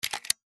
Звуки дробовика
Звук заряжания патрона в дробовик